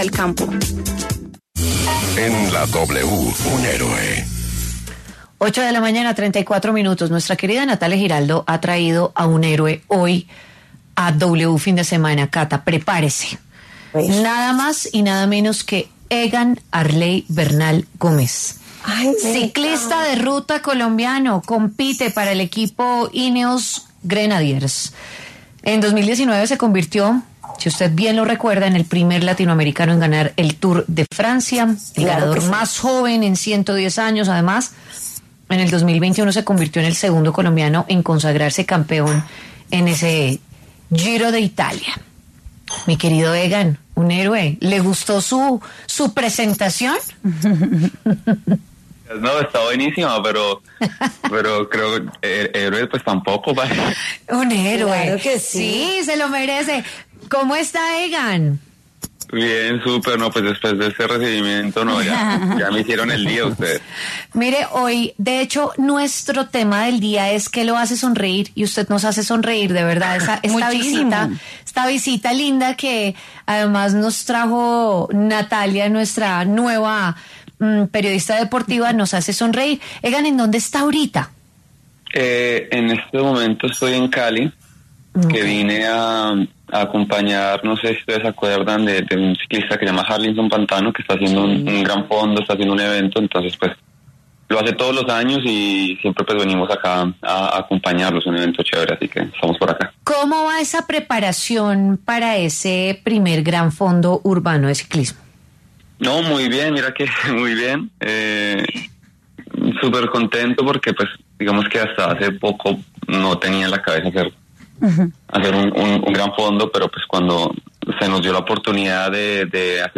El ciclista colombiano estuvo en los micrófonos de W Fin de Semana y habló en la intimidad de sus sueños profesionales más cercanos y sobre el gran fondo que liderará el próximo 17 de noviembre en Bogotá.